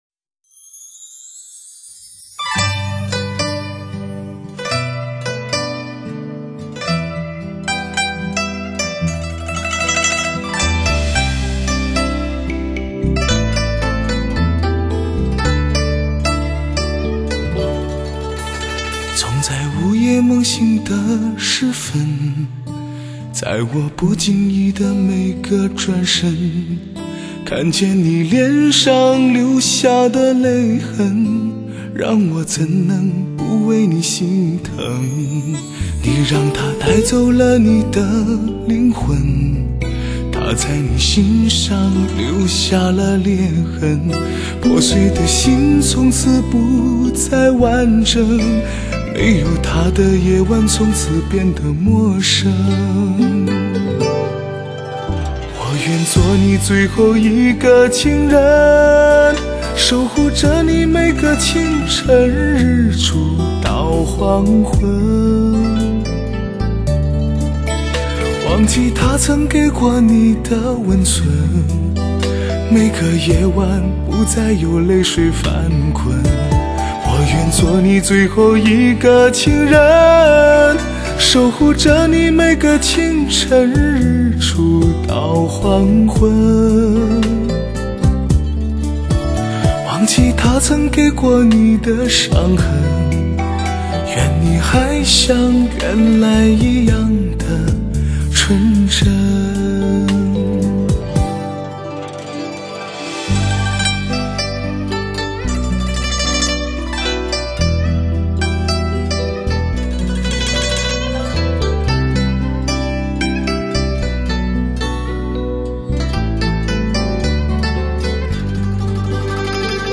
充满情感的嗓音魅力，是时间无法改变的，也是永不褪色的好声音。 温润如玉的男中音，醇厚柔和的质感颗粒，铅华尽洗的经典。